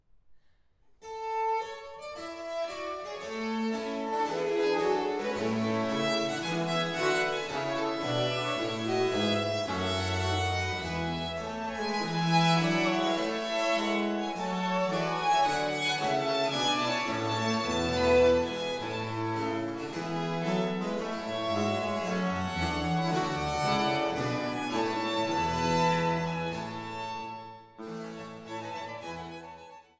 Violoncello
Theorbe
Cembalo, Polygonalspinet